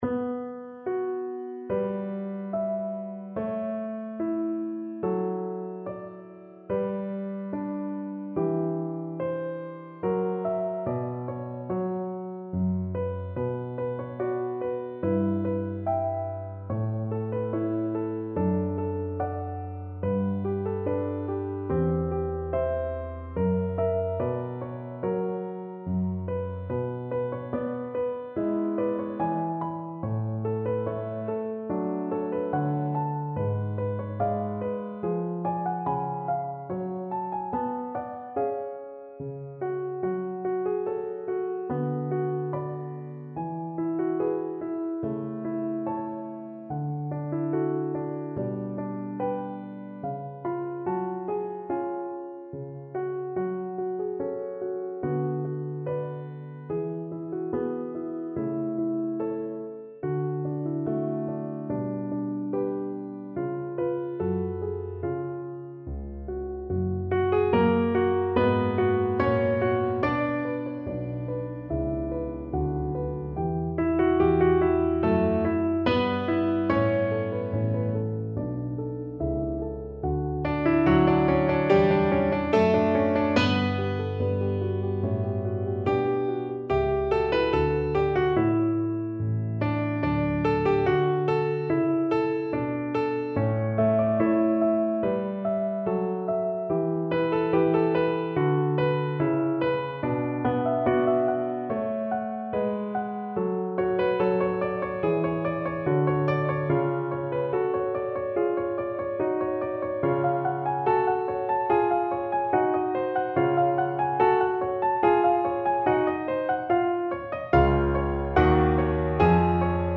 INSTRUMENTS Piano Solo
GENRES Baroque Period, Sacred, Christmas, Easter, Recital